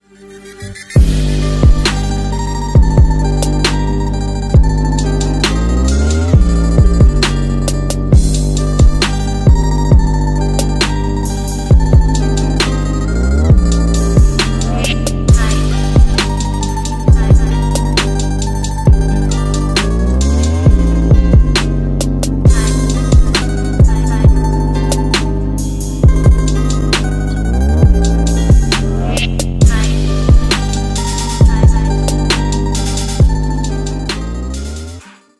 Ремикс
без слов